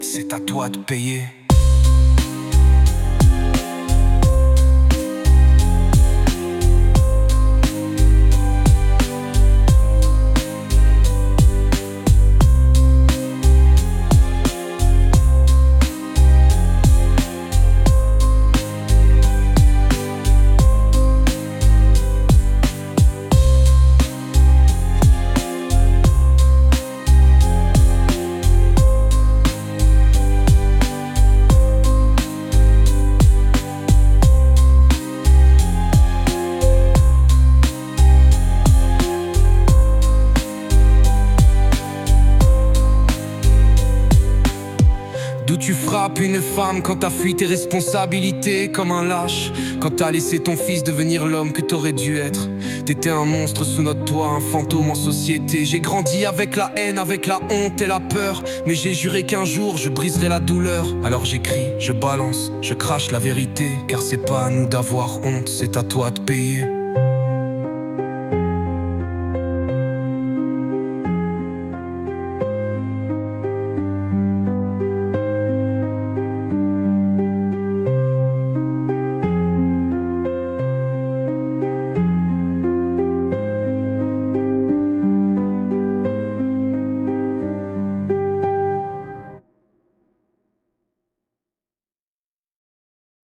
Alternative 4 : Version narrative prolongée